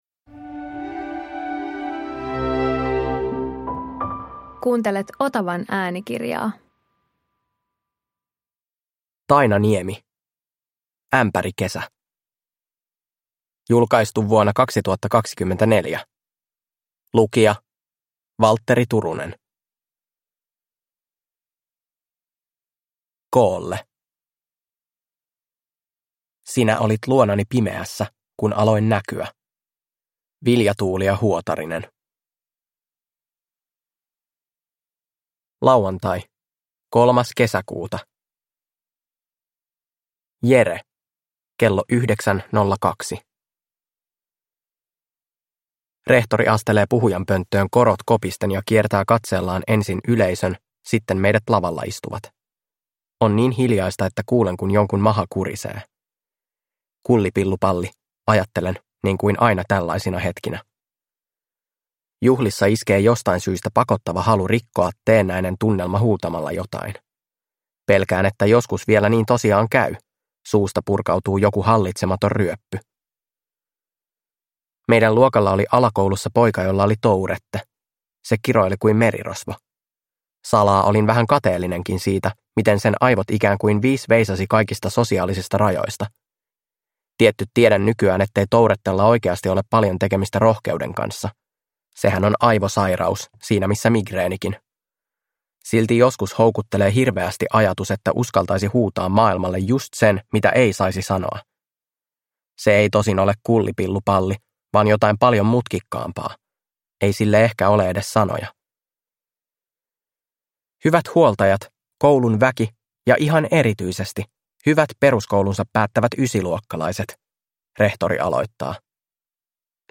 Ämpärikesä – Ljudbok